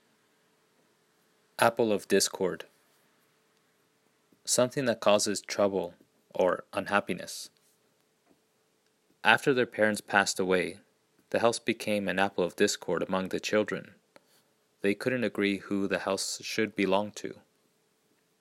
英語ネイティブによる発音は下記のリンクをクリックしてください。
appleofdiscord.mp3